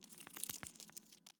Rustle1.ogg